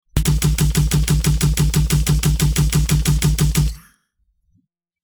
Laser Blast 02
Laser_blast_02.mp3